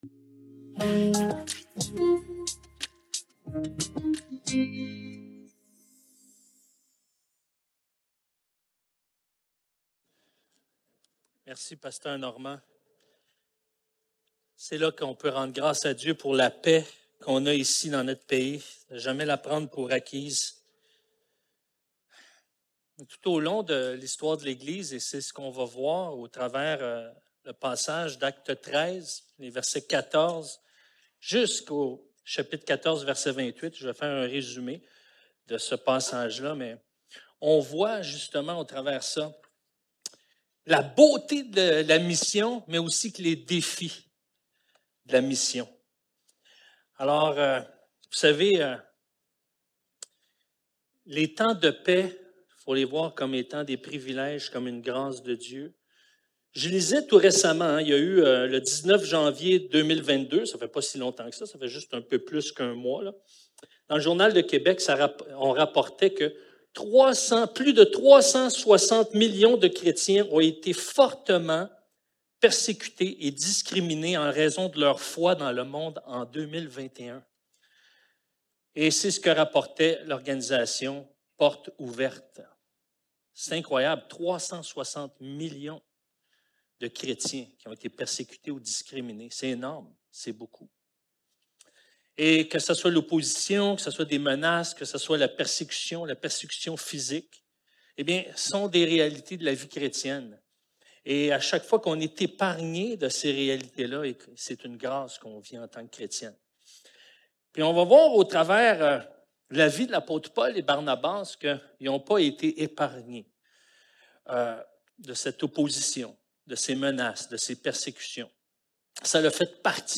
14-14:28 Service Type: Célébration dimanche matin Envoyés #26 Là où l'Évangile est prêché